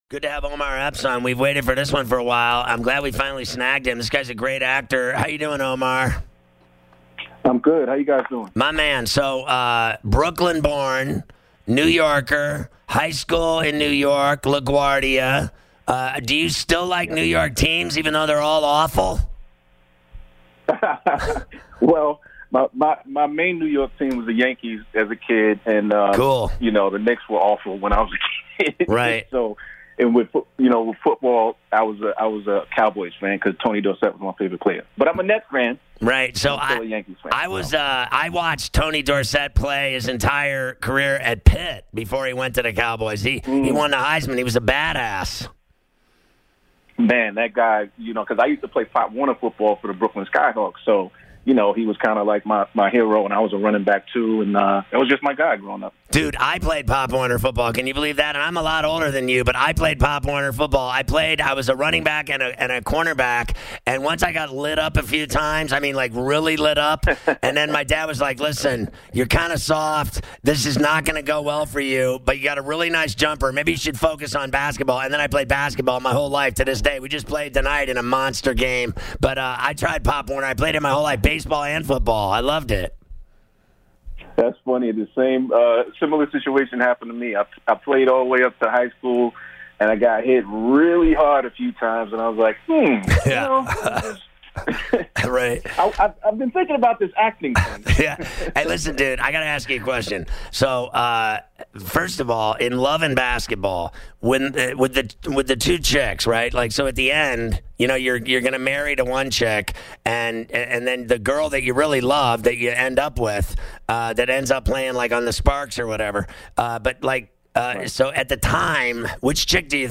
Omar Epps Interview